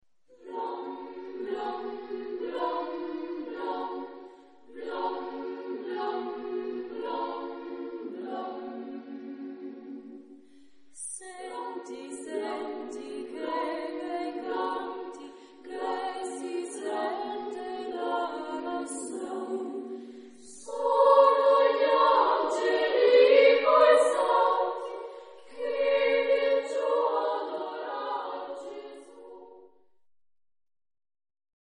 Genre-Style-Form: Christmas carol
Mood of the piece: joyous ; lively
Type of Choir: SSSA  (4 children OR women voices )
Tonality: G major